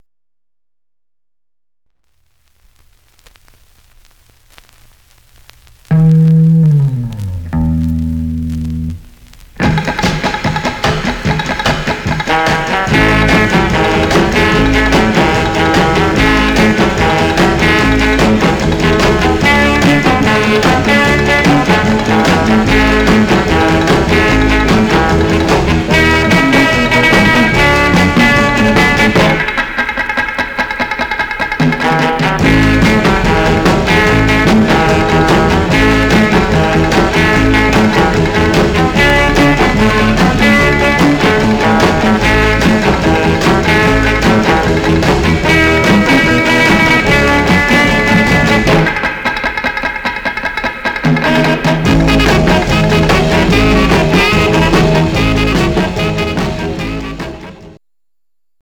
Some surface noise/wear
R & R Instrumental